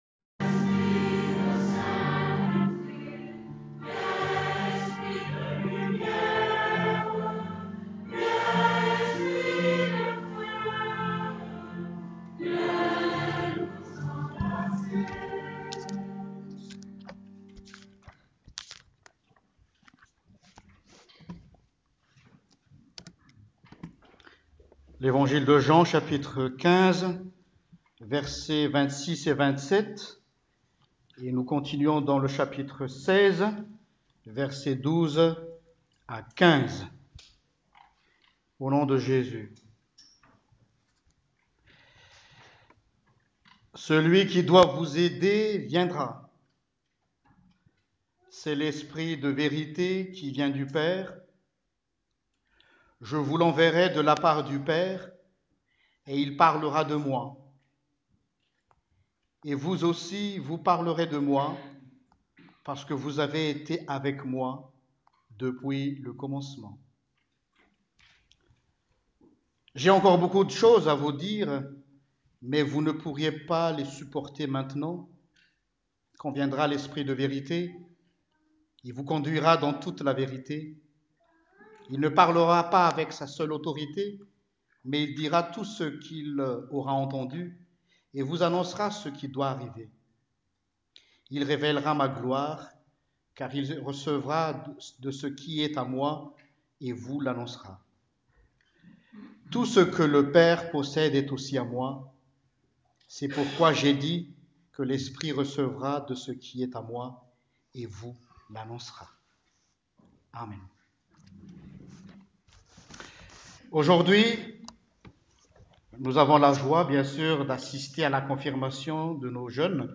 Prédication du 20 Mai 2018 (PENTECÔTE): MISSIO DEI | Eglise Protestante Unie de Namur
predication-pentecote-2018.m4a